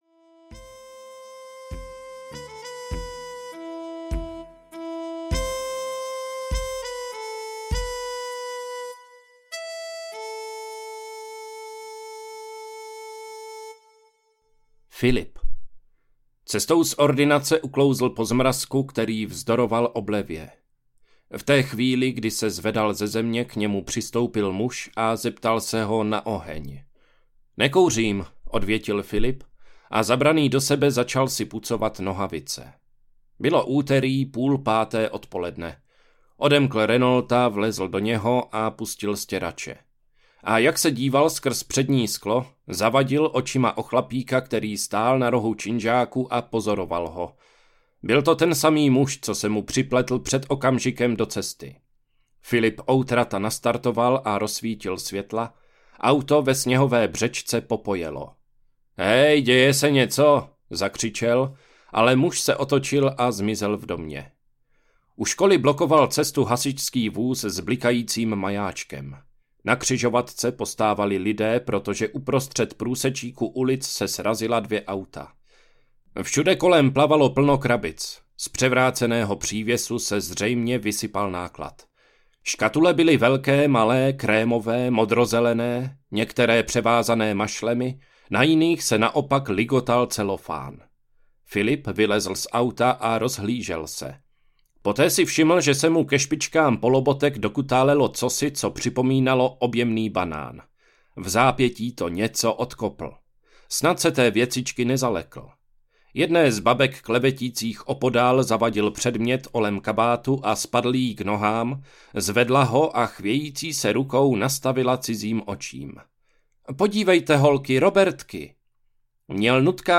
Kanibal audiokniha
Ukázka z knihy